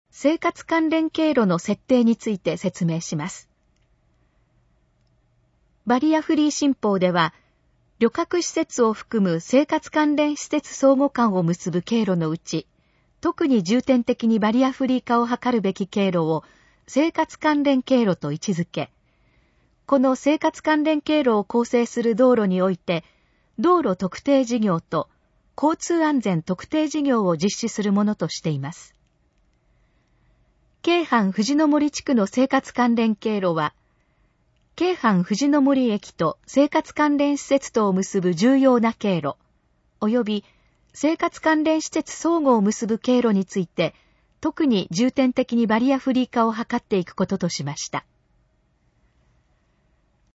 以下の項目の要約を音声で読み上げます。